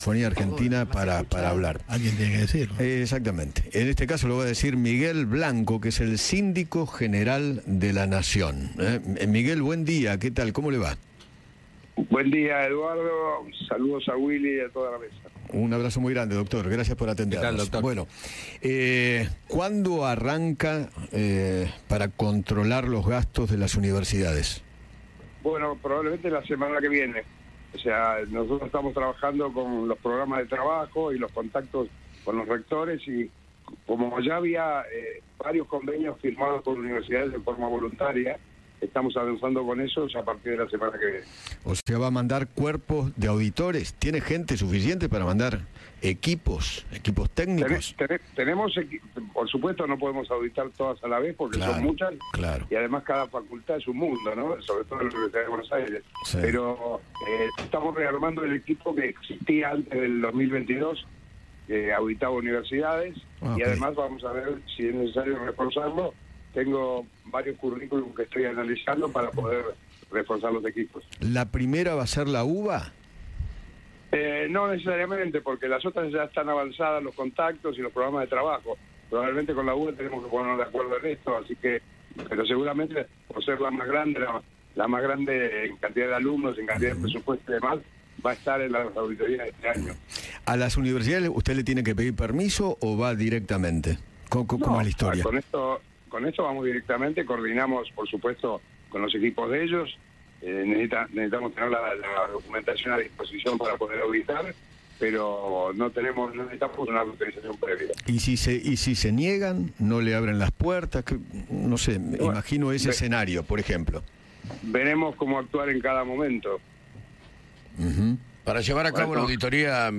Miguel Blanco, Síndico General de la Nación, habló con Eduardo Feinmann acerca del rol de la SIGEN sobre las universidades públicas y el control de los fondos.